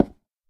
wood1.ogg